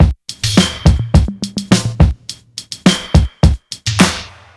I Really Like You Drum Loop.wav